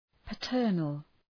Προφορά
{pə’tɜ:rnəl}